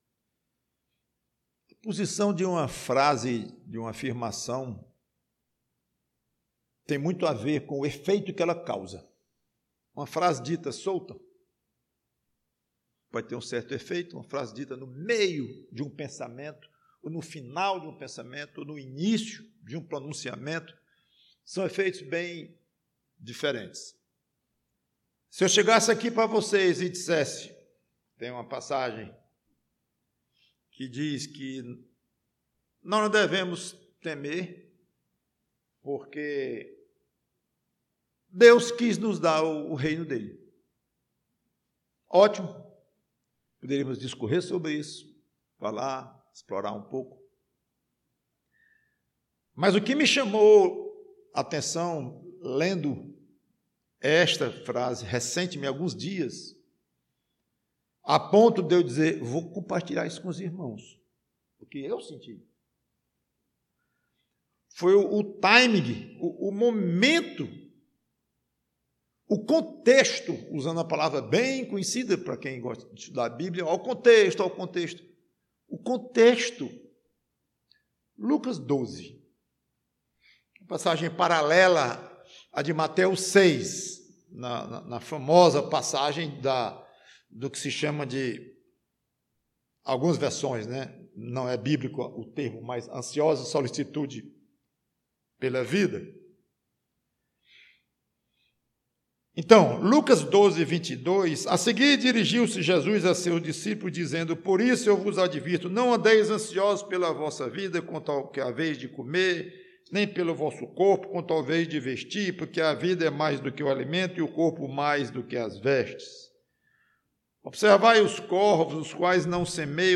PREGAÇÃO O reino de Deus... dado a mim???